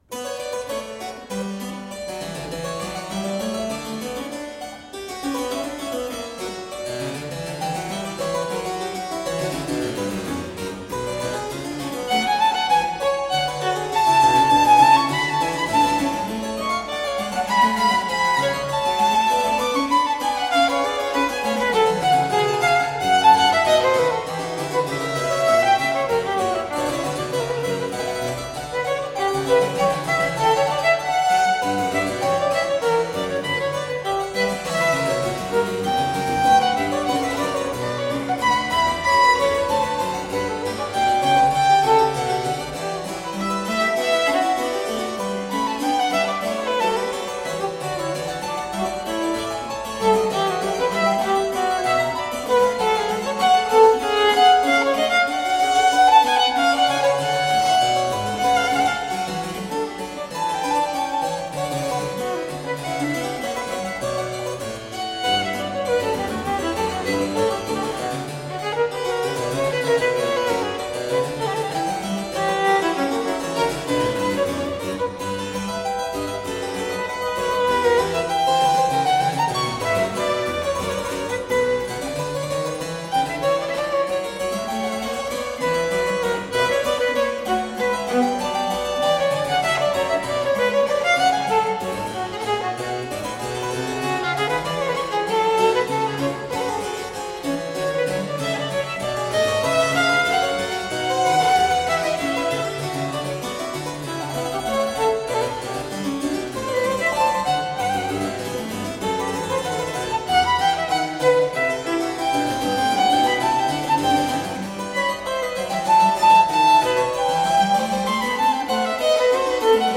Baroque violin & harpsichord.